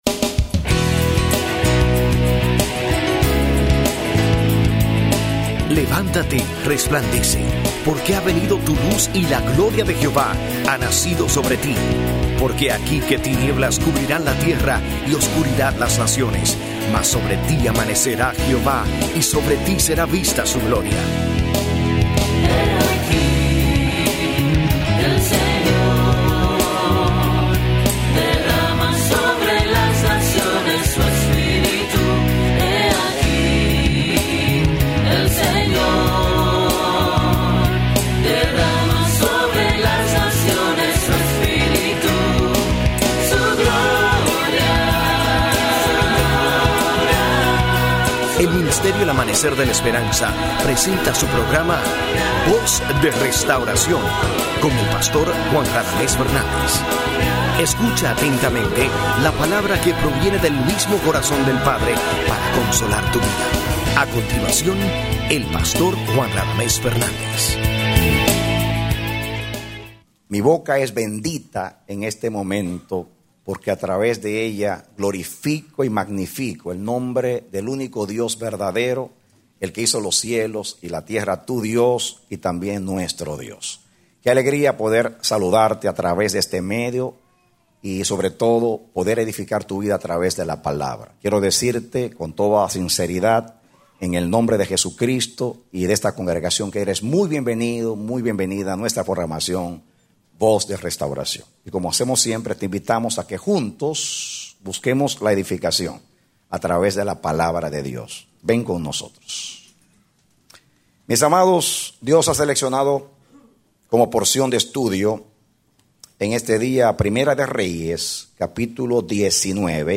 Mensaje: “¿Que haces Aquí Elías?”
Predicado Julio 5, 2009